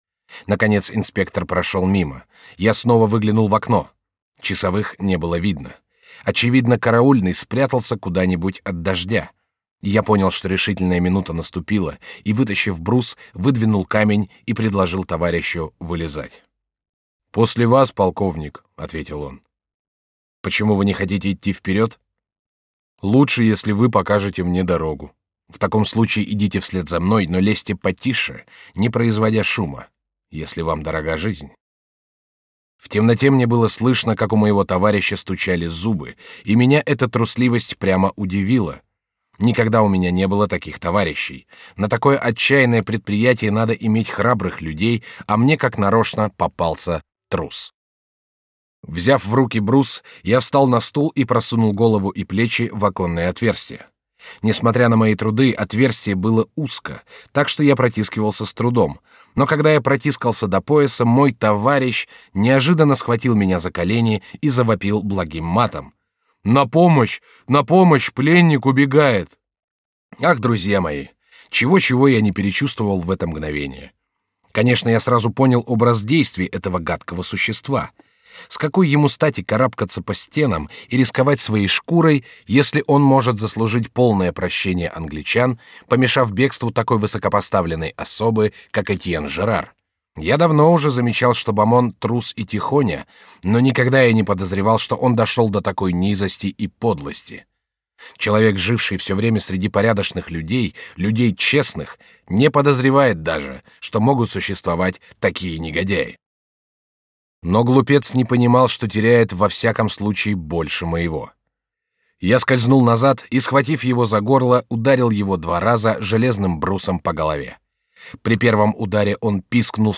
Аудиокнига Подвиги бригадира Жерара | Библиотека аудиокниг